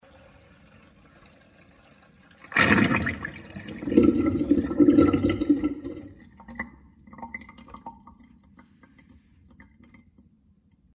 Звуки водопровода
На этой странице собраны разнообразные звуки водопровода: от тихого журчания до резких стуков в трубах.